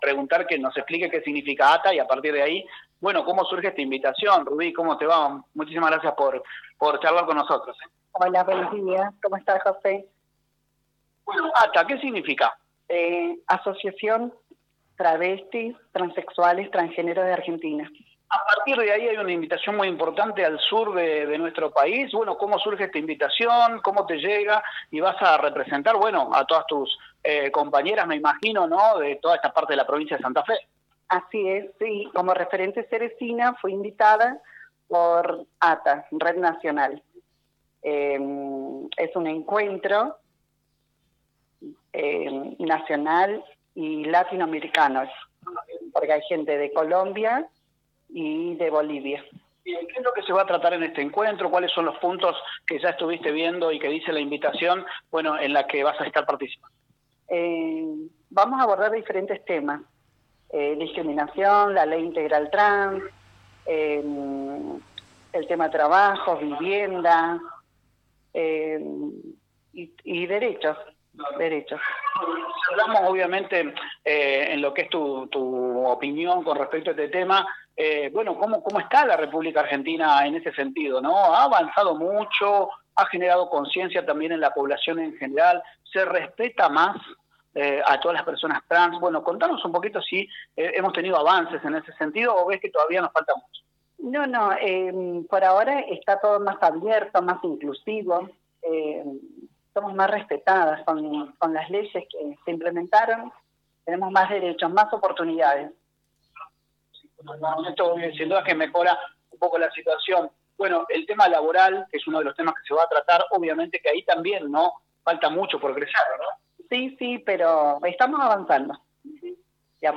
En una entrevista que realizamos